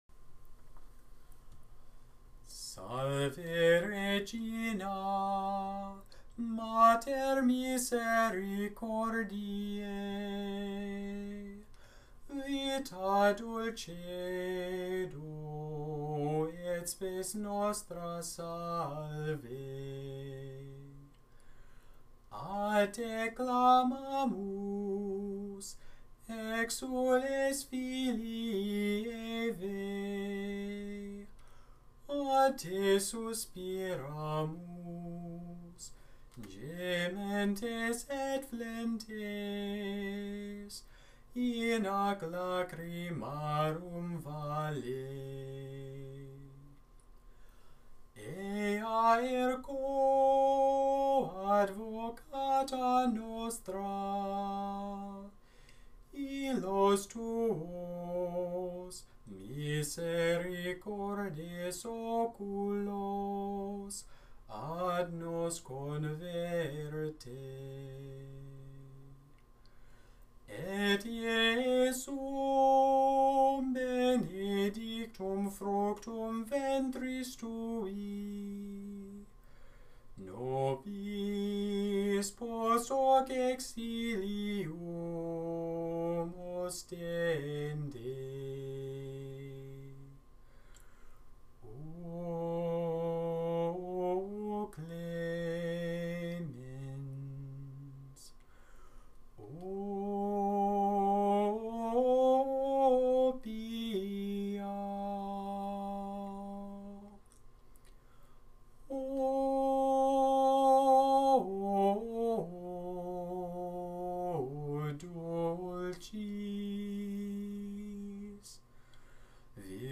Gregorian, Catholic Chant Salve Regina